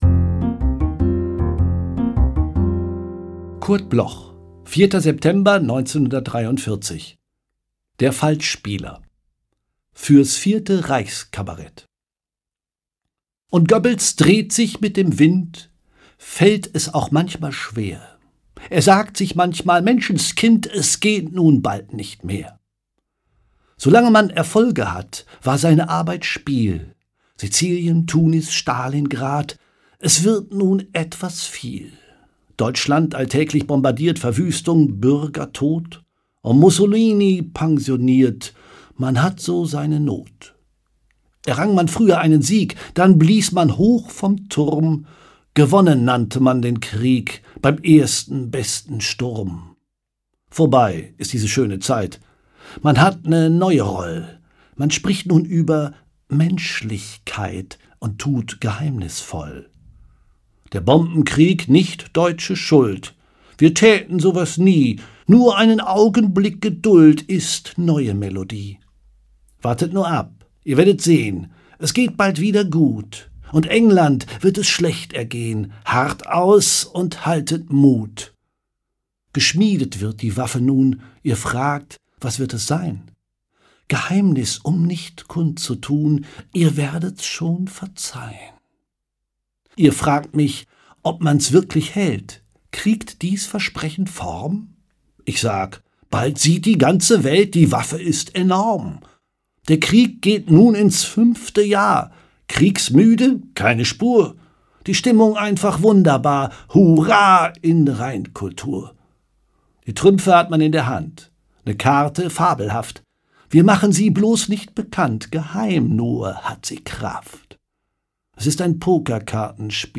performed by Peter Lohmeyer
Peter-Lohmeyer_FALSCHSPIELER_mit-Musik.m4a